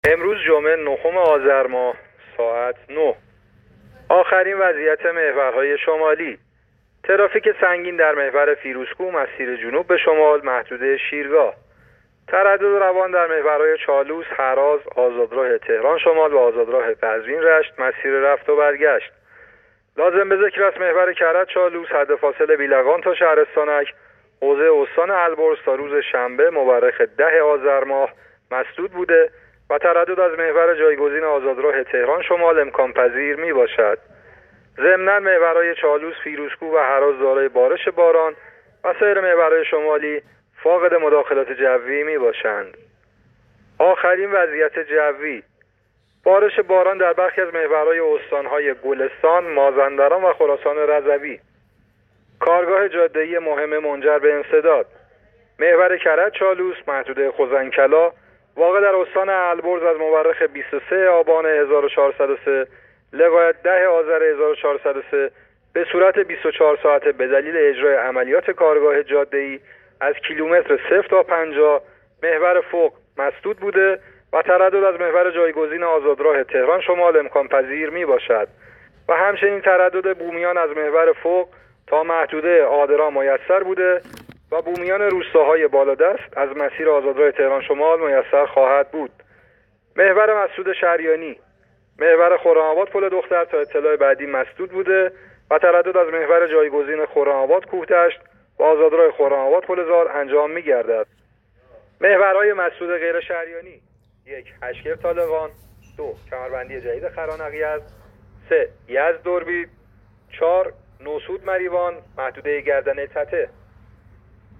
گزارش رادیو اینترنتی از آخرین وضعیت ترافیکی جاده‌ها تا ساعت ۹ نهم آذر